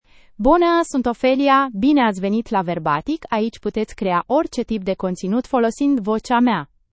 FemaleRomanian (Romania)
OpheliaFemale Romanian AI voice
Voice sample
Ophelia delivers clear pronunciation with authentic Romania Romanian intonation, making your content sound professionally produced.